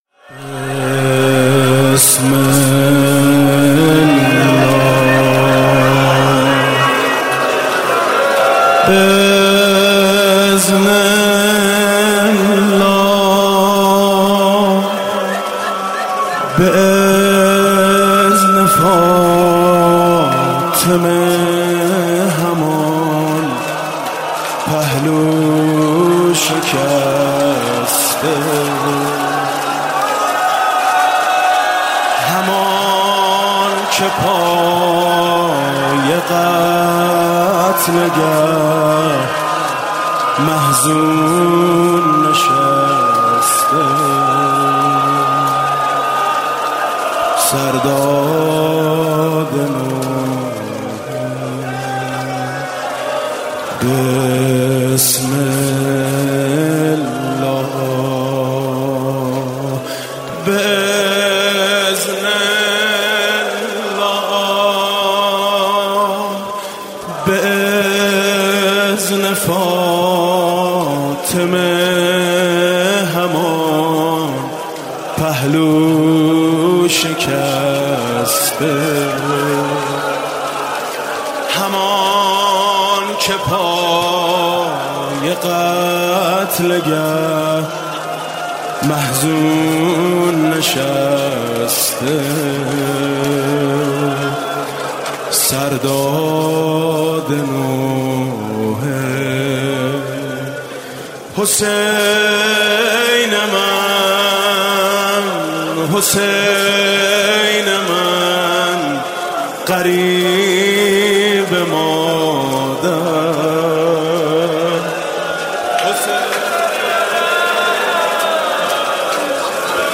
مداحی میثم مطیعی؛